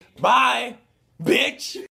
Звуки бай-бай